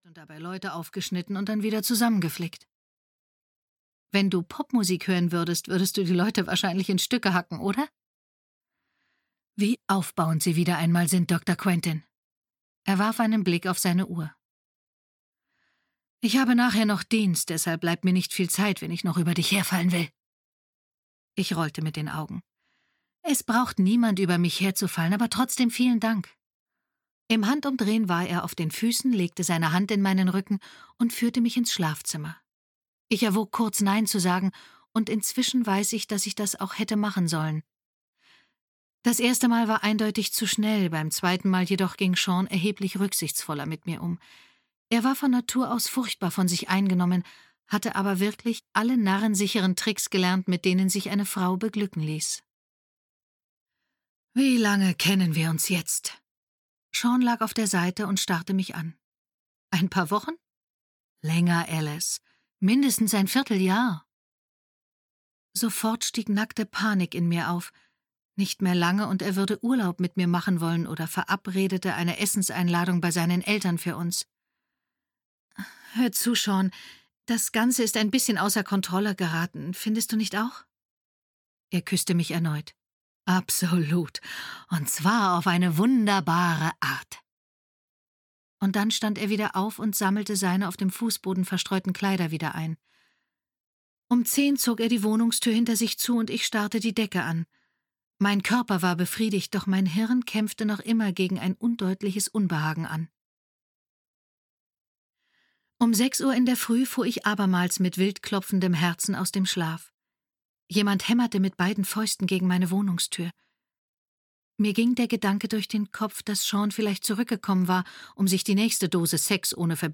Im Totengarten - Kate Rhodes - Hörbuch